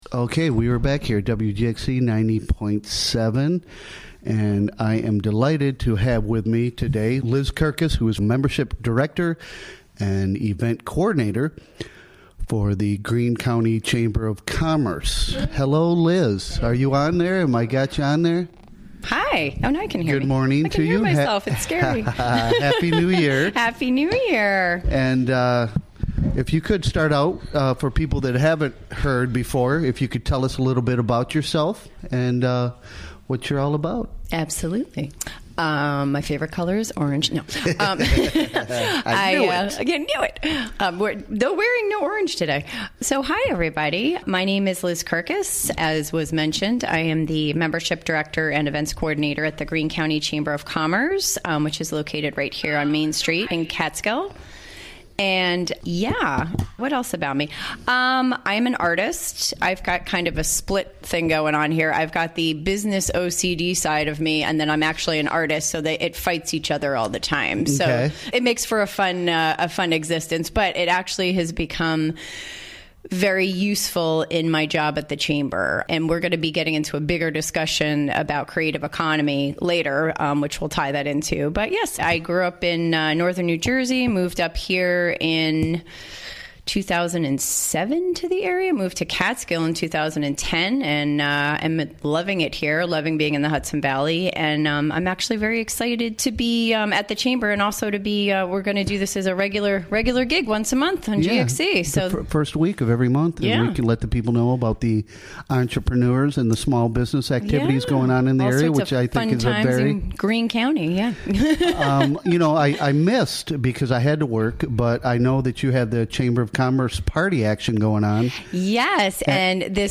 Interviewed
Recorded during the WGXC Morning Show, Tue., Jan. 2, 2018.